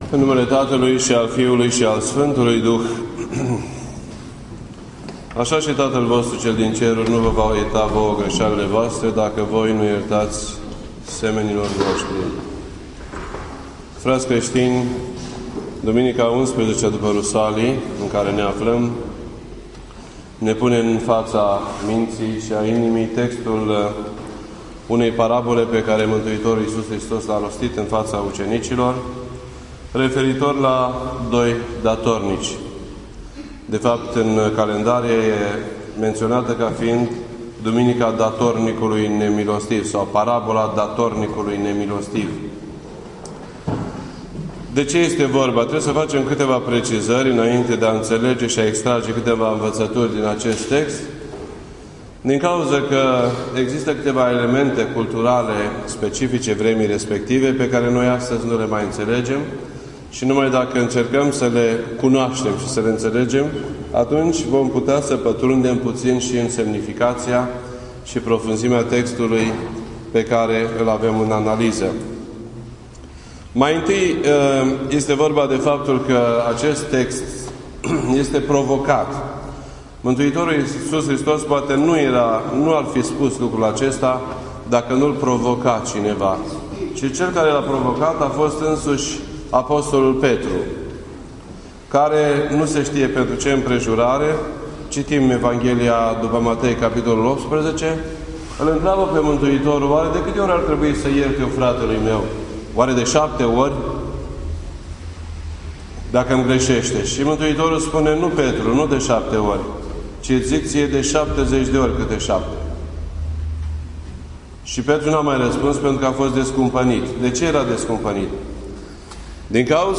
This entry was posted on Sunday, August 24th, 2014 at 12:13 PM and is filed under Predici ortodoxe in format audio.